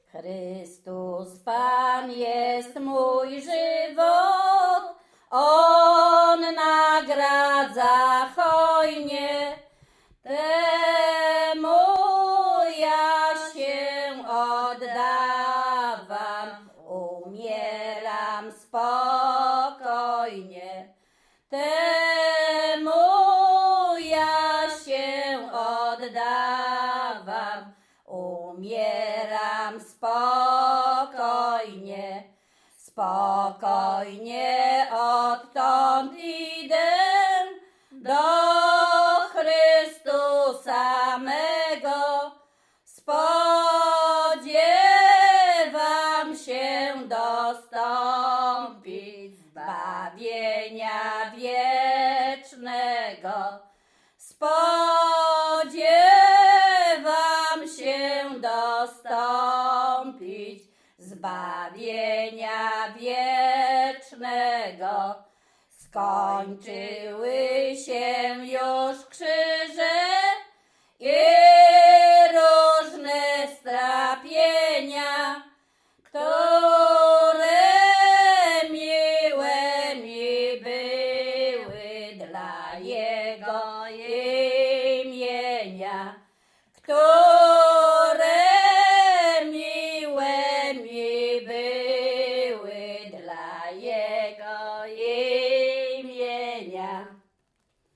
Śpiewaczki z Czerchowa
Łęczyckie
Pogrzebowa
Array nabożne katolickie pogrzebowe